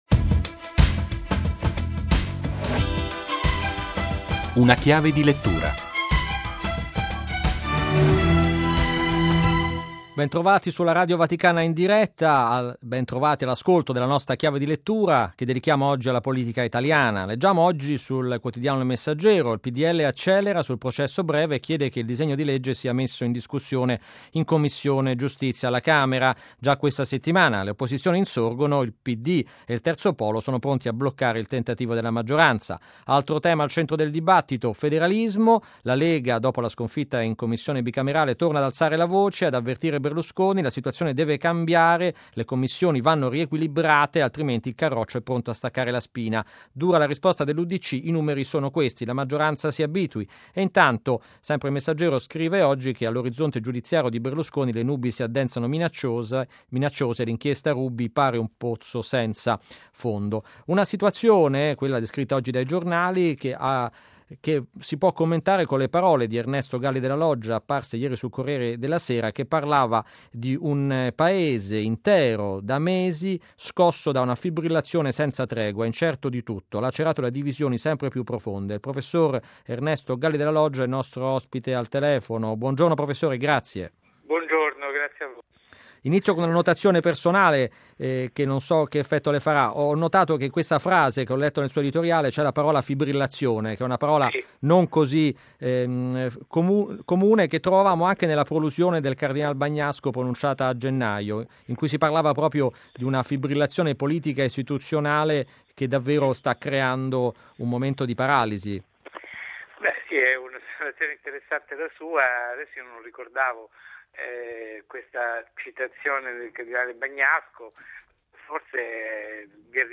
Ernesto Galli della Loggia, storico, editorialista del Corriere della Sera